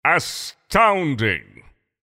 voice_tier6_astounding.mp3